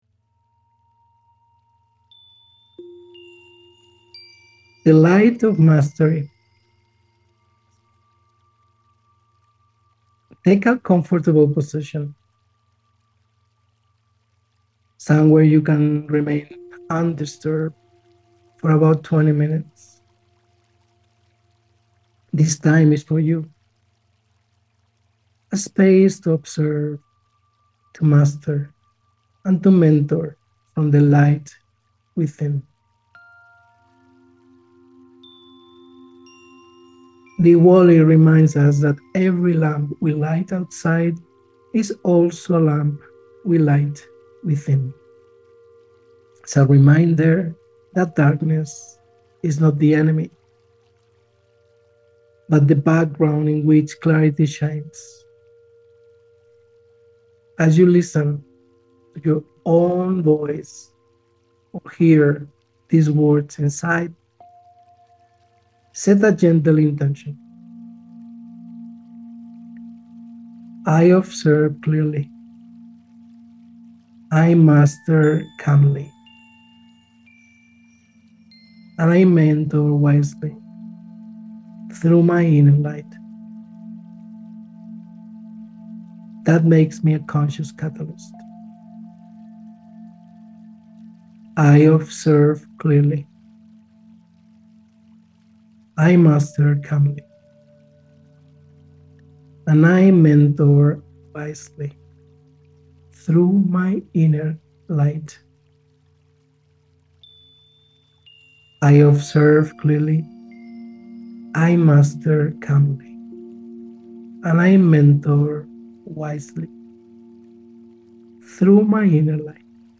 A Conscious Catalyst 22:01 Play Pause 6h ago 22:01 Play Pause Play later Play later Lists Like Liked 22:01 Episode Flow Total Duration: ~25 minutes (includes intro/outro, context, and 20-minute induction) 1.